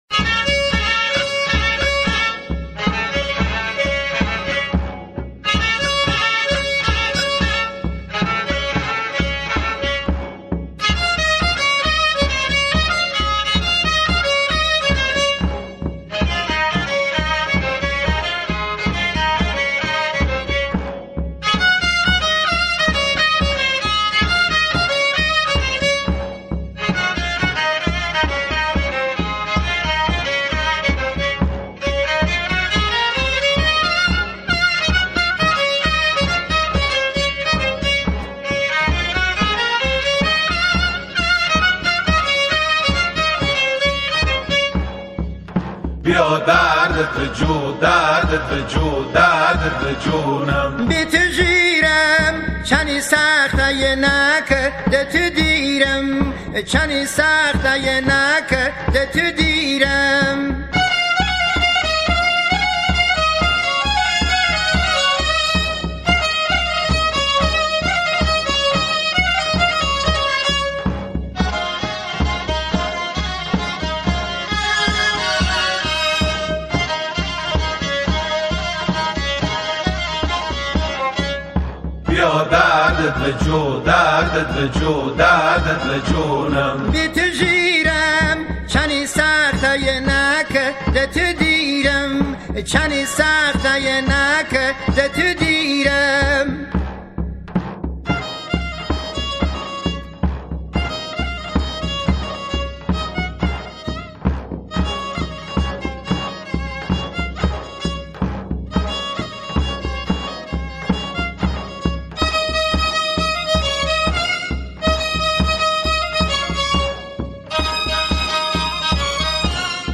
همخوانی شعری عاشقانه به زبان لری
گروهی از همخوانان